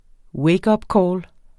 Udtale [ ˈwεjgʌbˌkɒːl ]